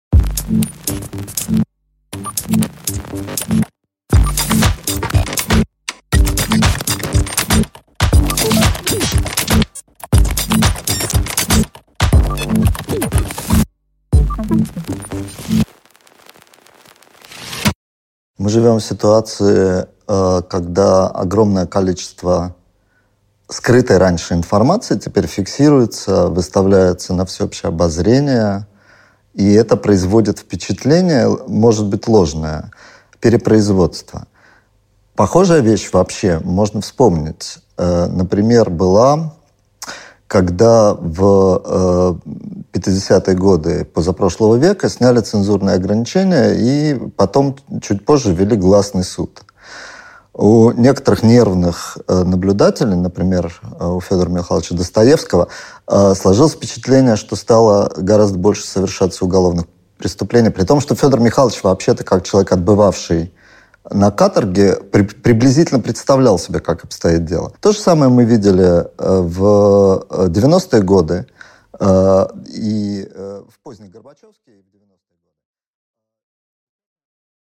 Аудиокнига Как информационные технологии изменили нашу жизнь | Библиотека аудиокниг